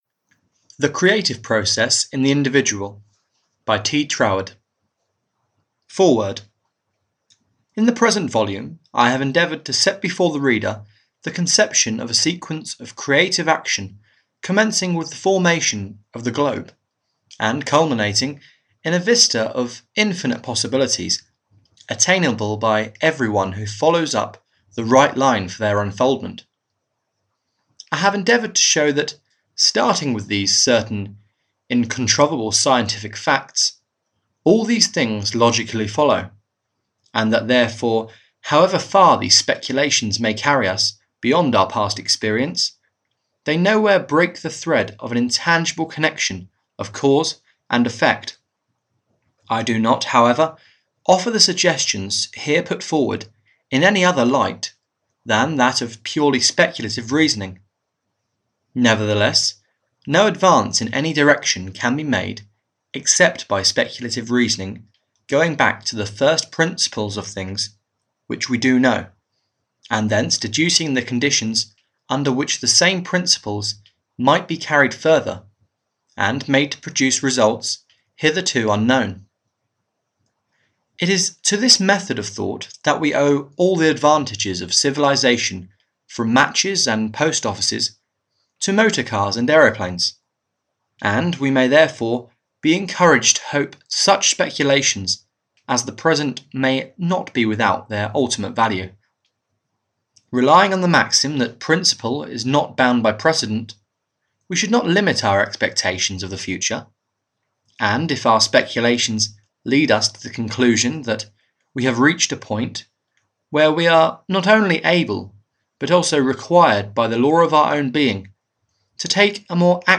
The Creative Process In The Individual (EN) audiokniha
Ukázka z knihy